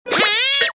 usagiHurtSFX.MP3